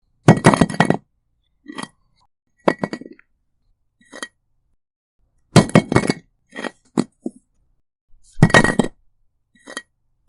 Single Brick Pickup Drop
Single Brick Pickup Drop is a free sfx sound effect available for download in MP3 format.
Single Brick Pickup Drop.mp3